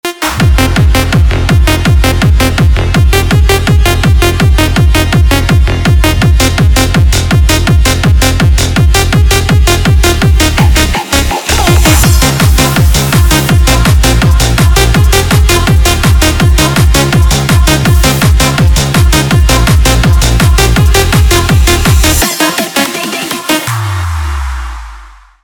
Категория: Громкие звонки и рингтоны